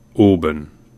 Oban (/ˈbən/
OH-bən;[3] Scottish Gaelic: An t-Òban [ən̪ˠ ˈt̪ɔːpan] meaning The Little Bay) is a resort town within the Argyll and Bute council area of Scotland.